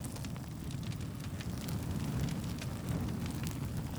Weapon 14 Loop (Flamethrower).wav